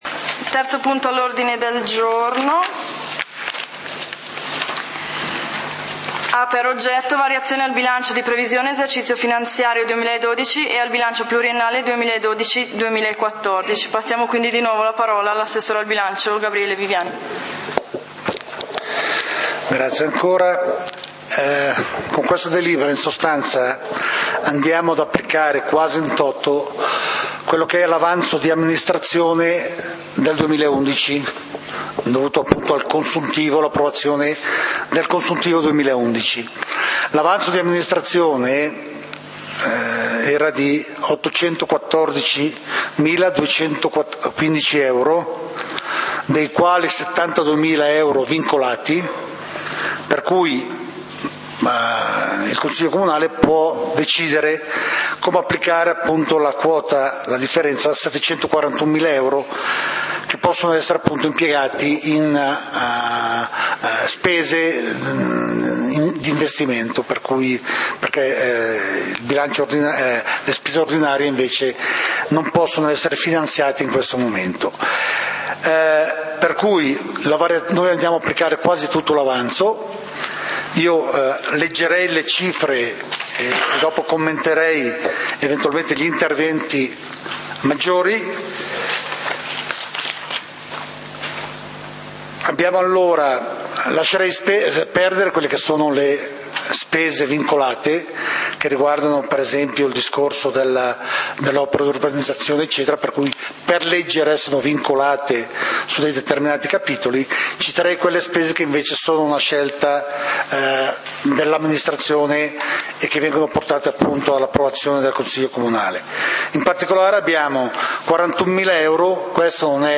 Punti del consiglio comunale di Valdidentro del 24 Agosto 2012